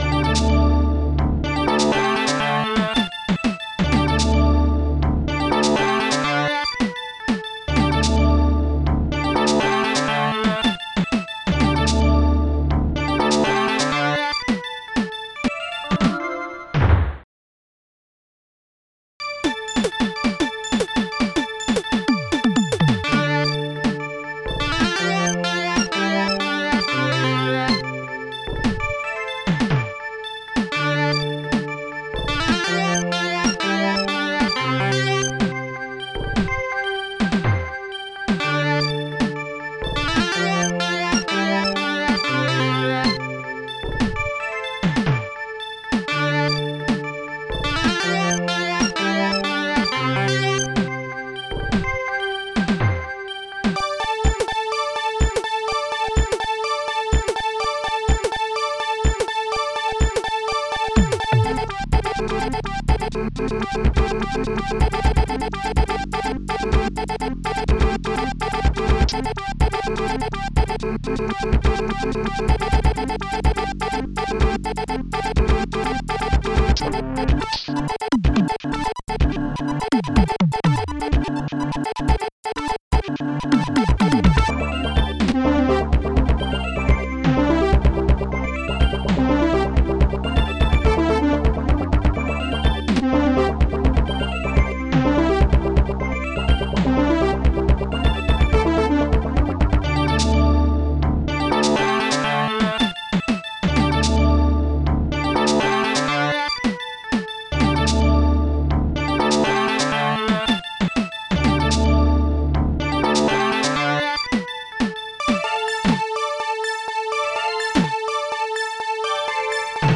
SoundTracker Module
2 channels